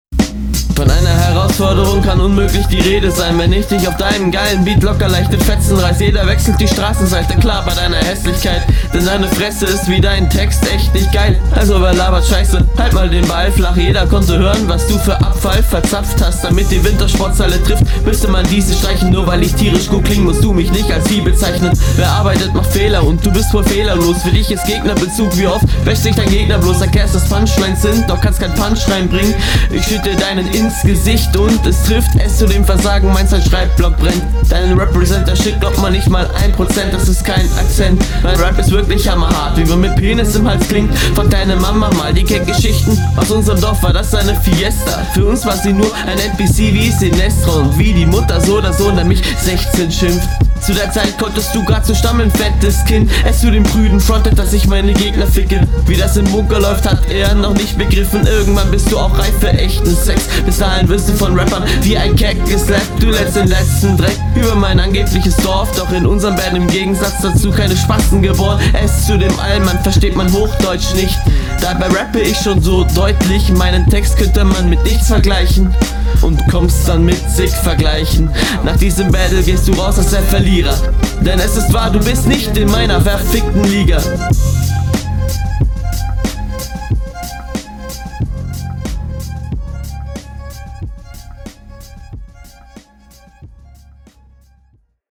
diese Triolen passagen klingen sehr unrund